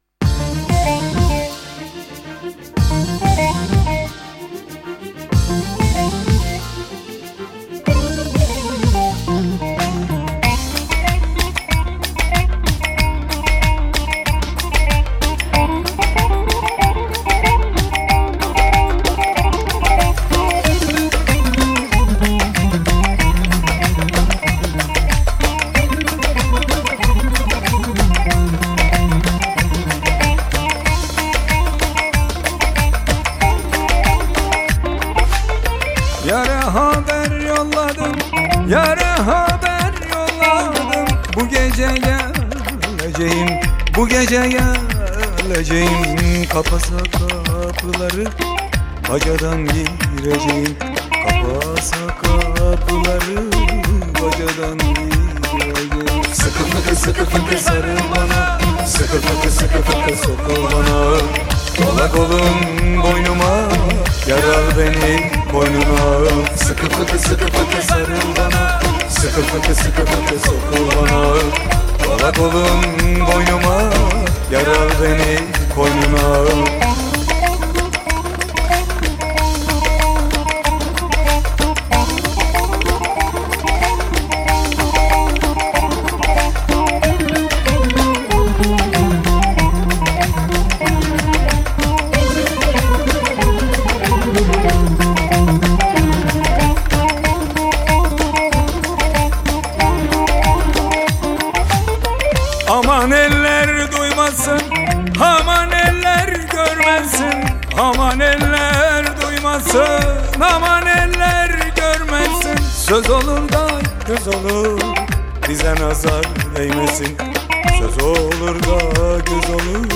Eser Şekli : Pop Fantazi